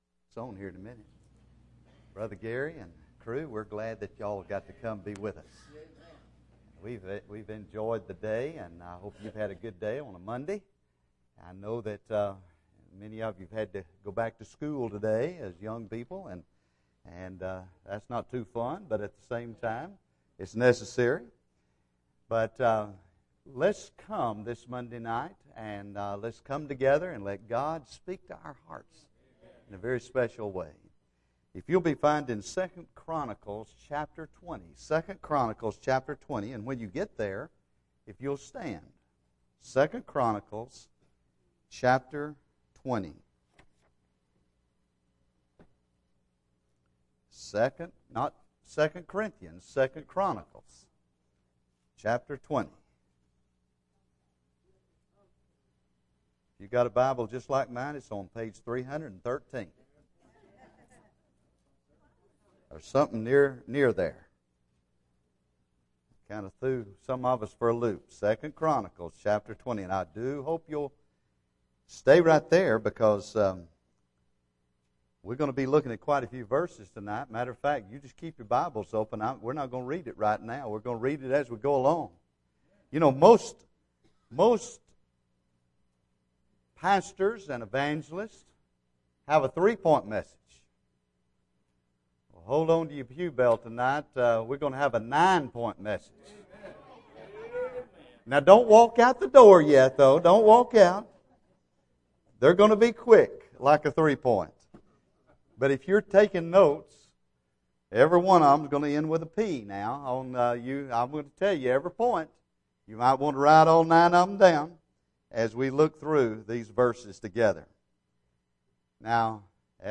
Bible Text: II Chronicles 20:1-2 | Preacher